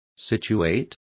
Complete with pronunciation of the translation of situating.